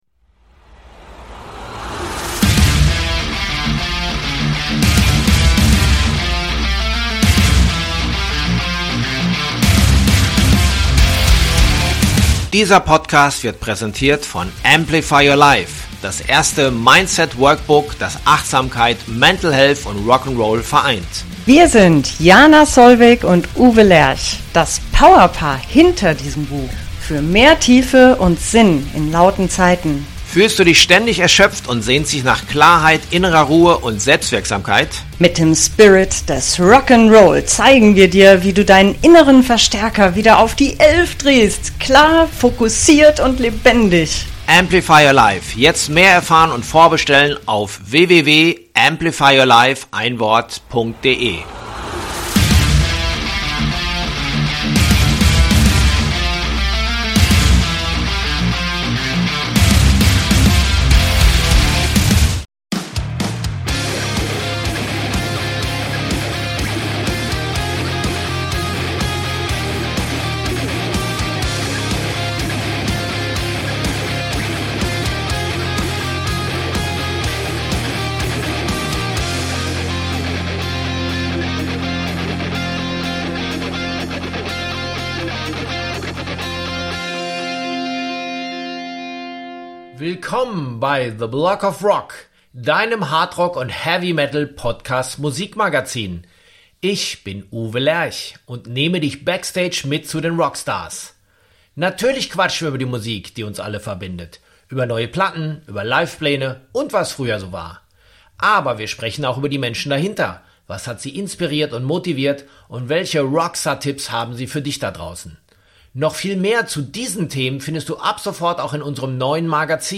In dieser Folge treffe ich Hans Ziller in seinem heimischen Garten und spreche mit ihm über die Neuveröffentlichungen der ersten drei BONFIRE-Alben, die mit der aktuellen Besetzung komplett neu und mit mehr Metal eingespielt wurden. Dazu gibt es einen Ausblick auf das nächste Studioalbum plus ein Buchprojekt.